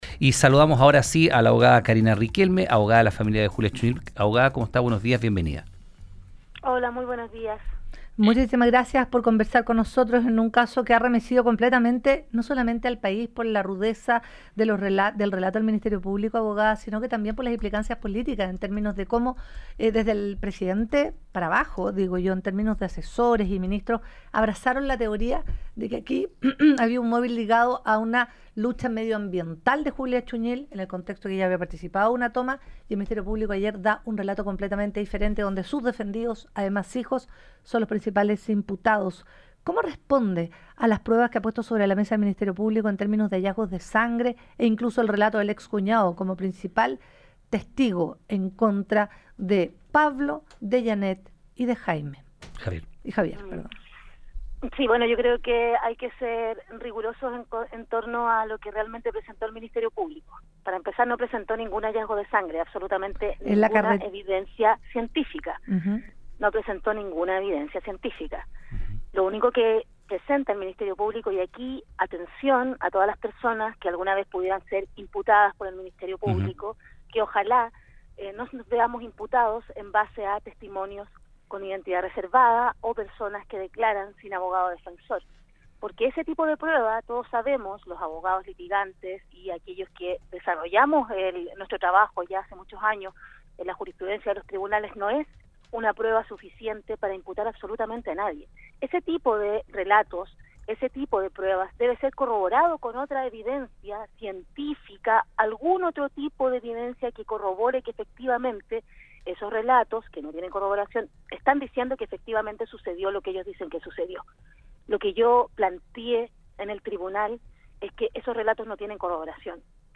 En conversación con Universo al Día